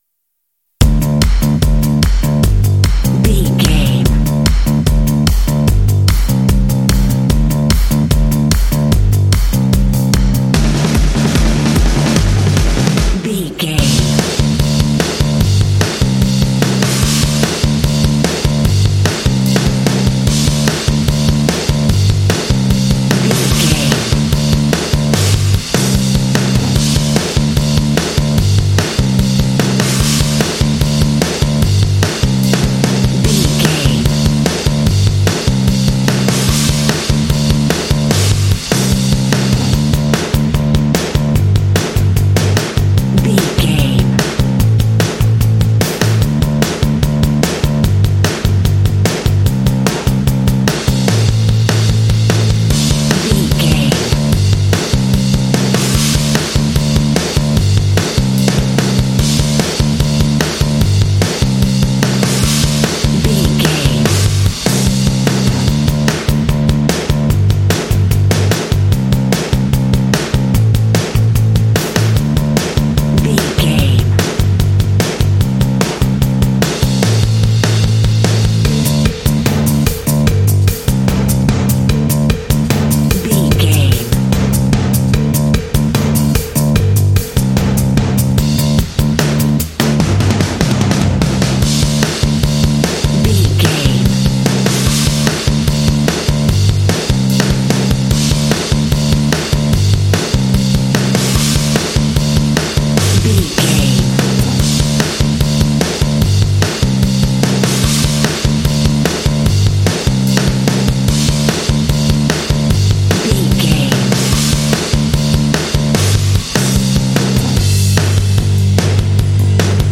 This cute indie track is ideal for action and sports games.
Uplifting
Ionian/Major
Fast
energetic
cheerful/happy
bass guitar
drums
classic rock
alternative rock